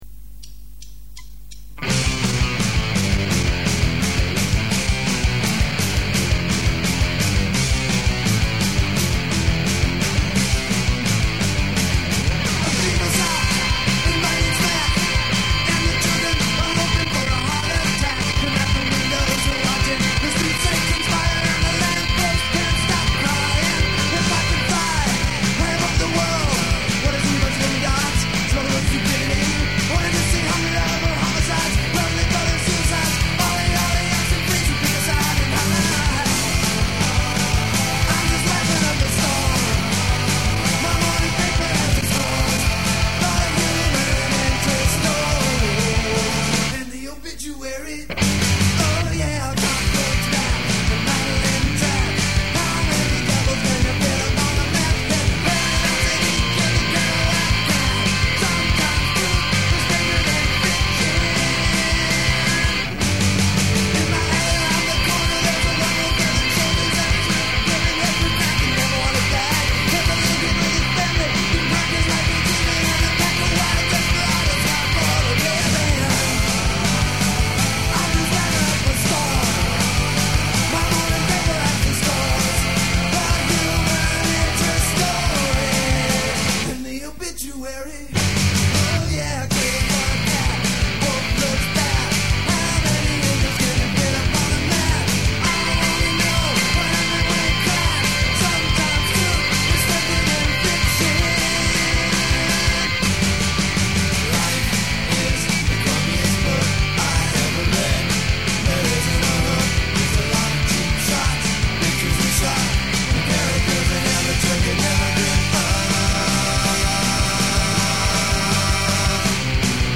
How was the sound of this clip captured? home demos